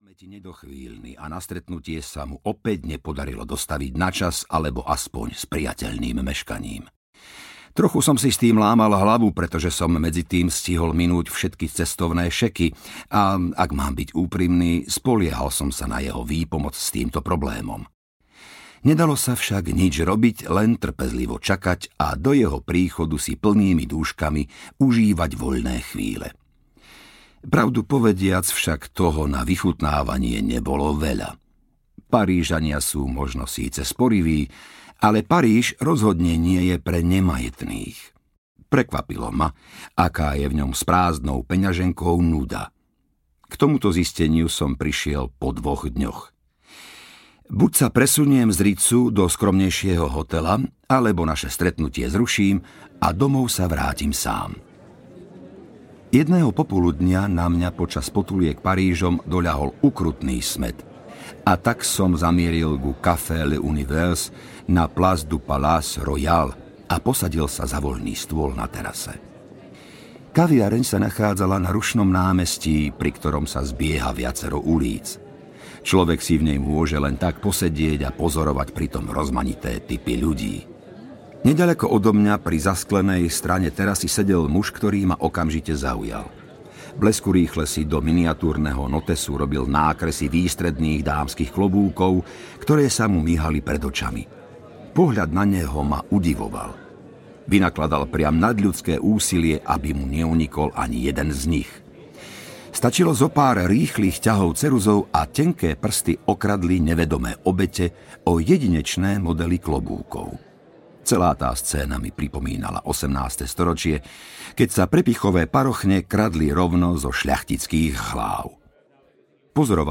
Vražda vo Wildshote audiokniha
Ukázka z knihy